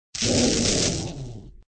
SDogShock.ogg